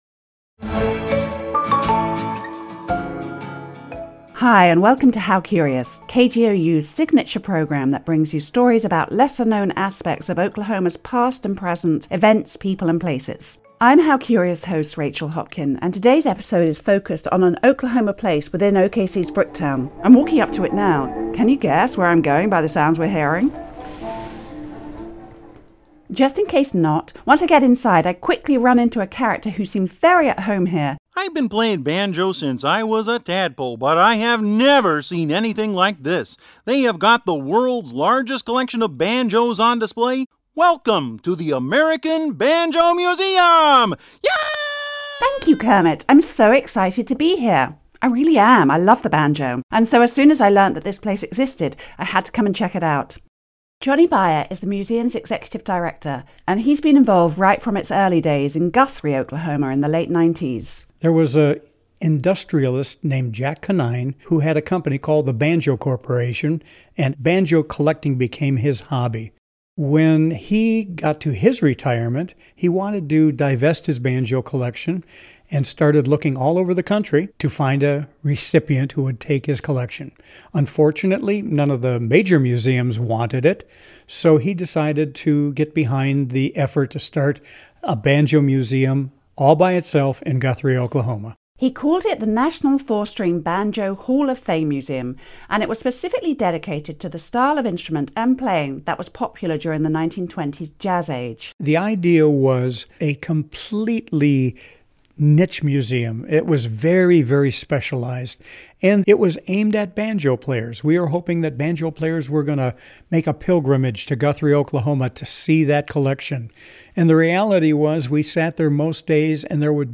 hc-banjo-mix-2-14-min-pod-w-tag.mp3